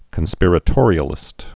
(kən-spîrə-tôrē-ə-lĭst)